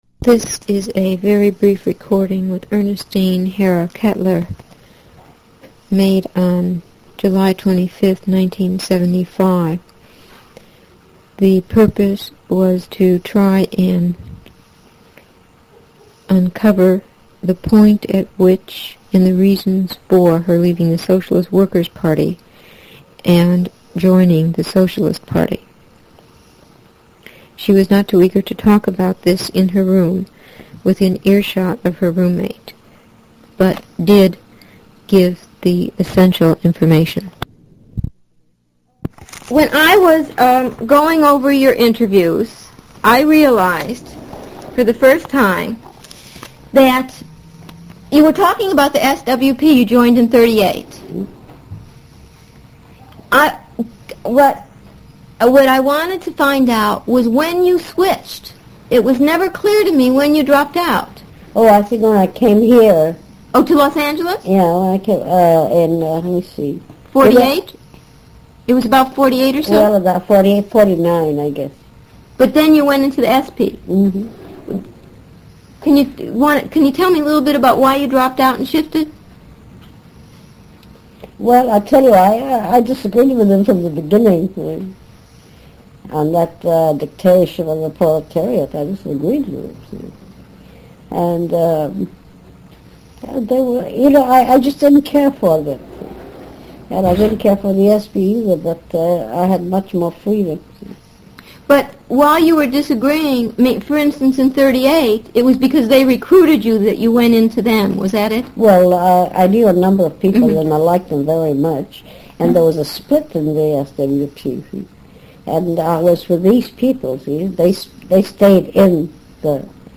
Although she was not eager to talk about this in her room within earshot of her roommate, she did provide the essential information. 7/25/1975